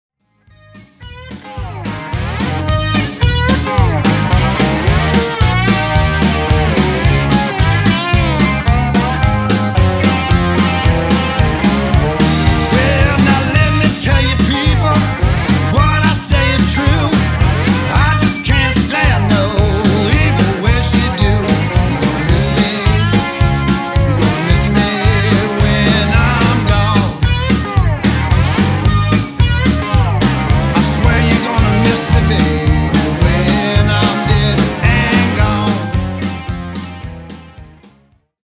The theme for this release is Red Hot Guitar